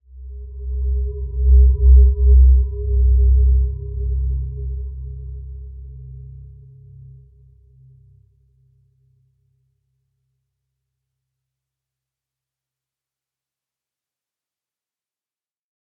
Dreamy-Fifths-C2-mf.wav